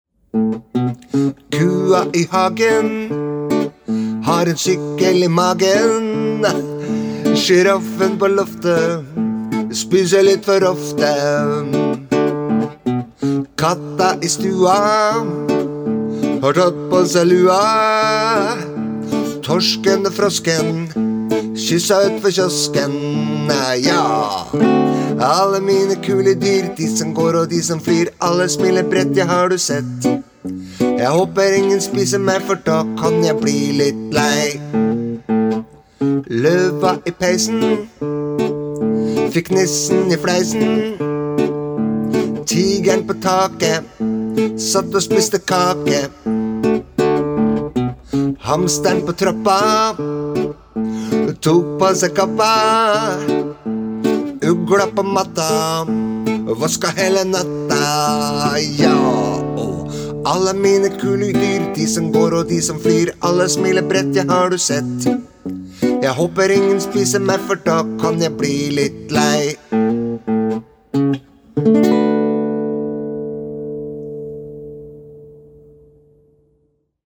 Onsdag 9. februar 2022:  KUA I HAGEN – BARNESANG (Sang nr 161)